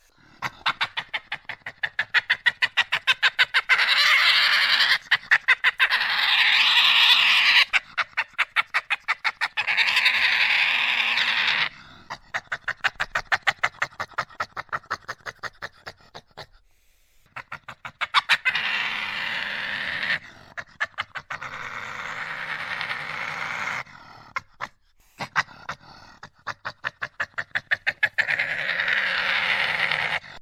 Звуки барсука
8. Зловещий рык n8. Яростное урчание